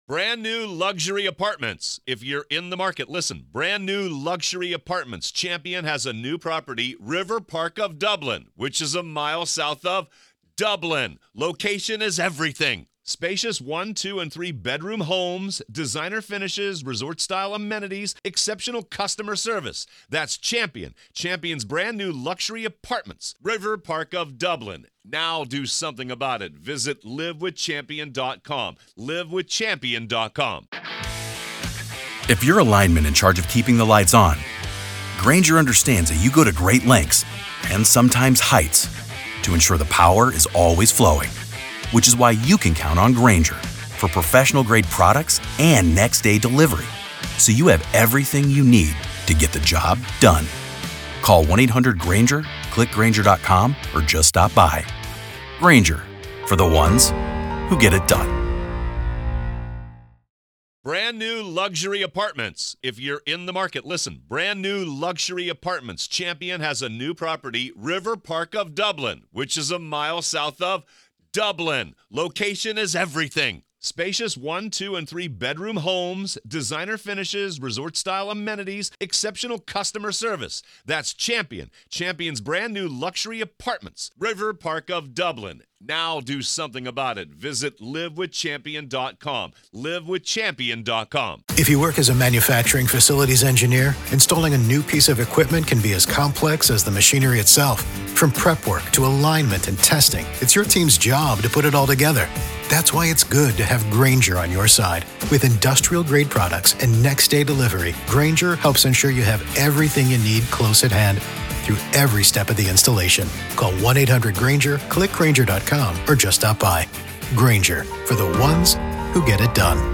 Court Audio-NEVADA v. Robert Telles DAY 4 Part 1